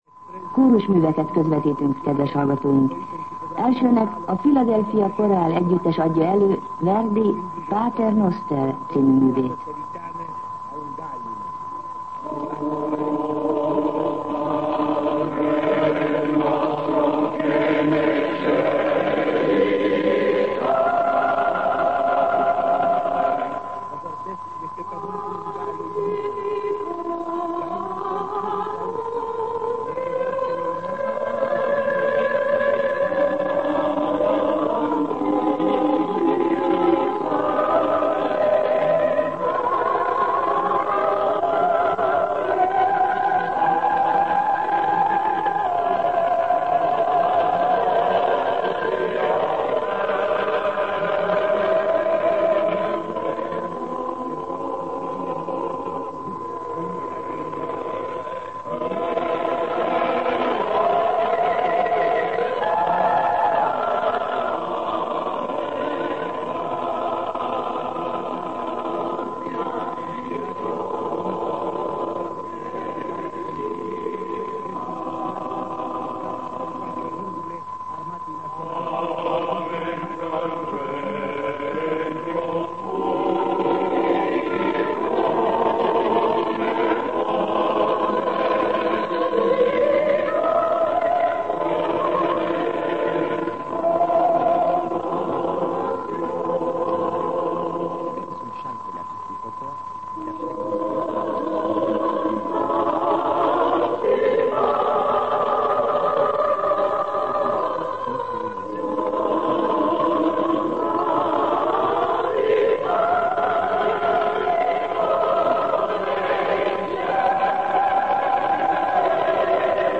Zene